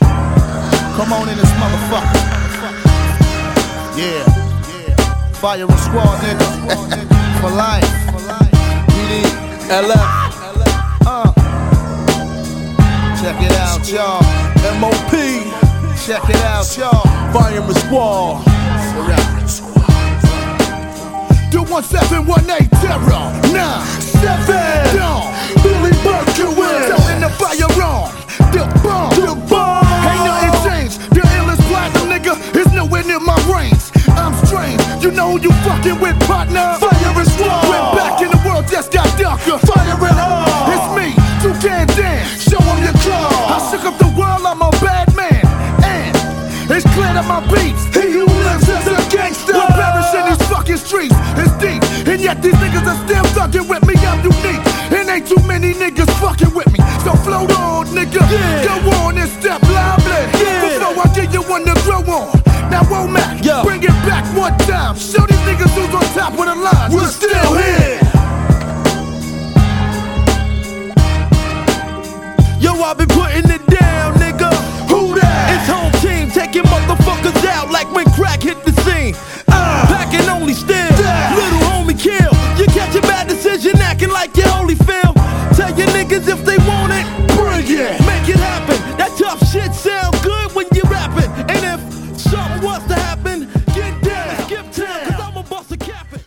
HIP HOP
EAST COAST G RAP !!